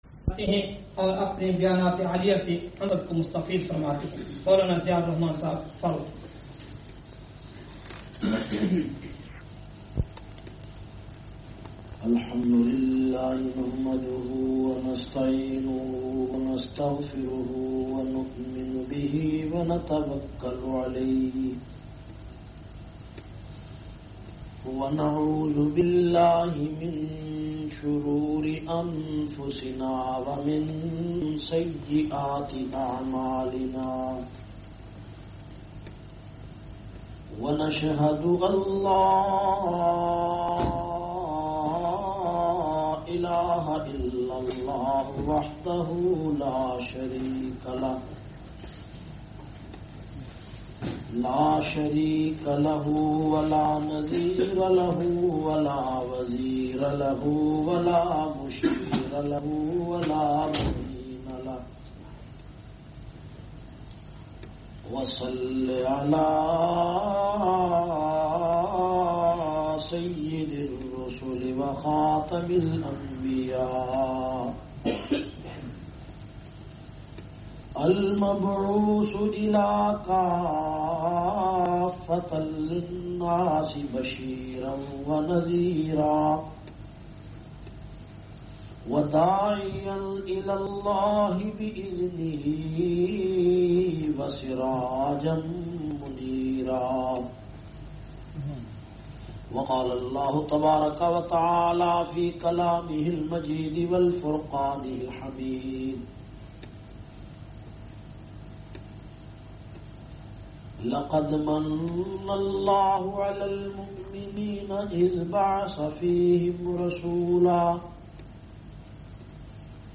359- Seerat e Mustafa Conference 11 Rabi ul Awwal Madrasa Taleem ul Quran  Edinburgh England.mp3